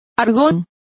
Also find out how argones is pronounced correctly.